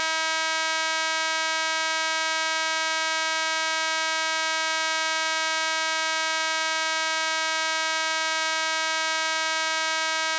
Federal Signal 52-024-1 Federal Signal Horn 24VDC Resonating
It provides the very loud sound for which an air–horn is usually required, but without the need for an expensive compressor.
• Produces 124 dBa @ 1m (114 dBa @ 10′) with a 305m (1,000 ft) range
• Penetrates high ambient noise levels – ideal for busy mining and construction sites
Model_52_Horn_audio_file.mp3